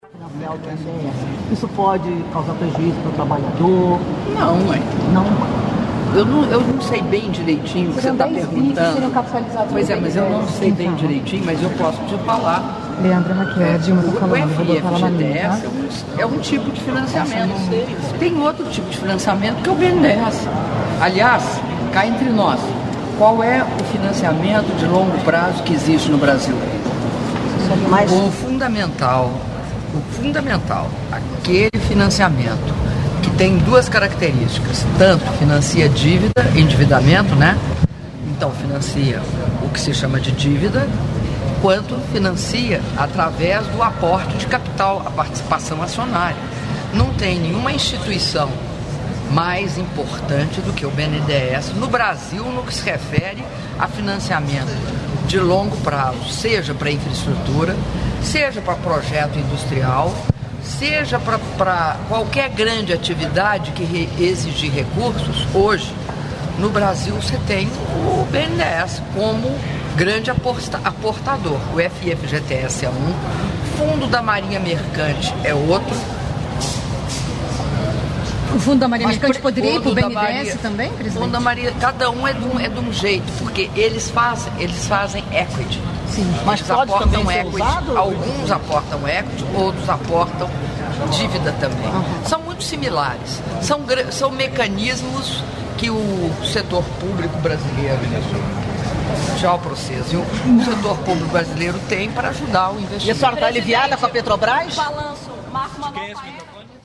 Áudio da entrevista coletiva concedida pela presidenta da República, Dilma Rousseff, na chegada ao Palácio Itamaraty (1min46s)